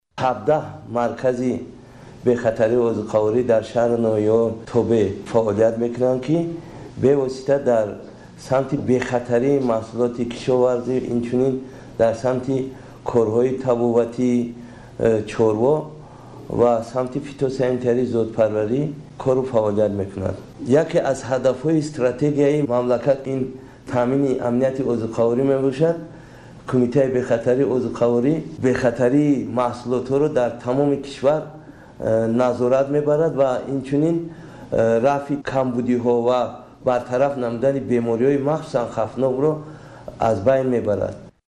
Ба гузориши радиои тоҷикии Садои Хуросон, ин кумита вазифаи озмоиши маҳсулоти ғизоиро дар Тоҷикистон дорад . Бобошоҳ Давлатшоҳзода-раиси Кумитаи бехатарии озуқавории раёсати ҷумҳурии Тоҷикистон дар ин бора тавзеҳ медиҳад :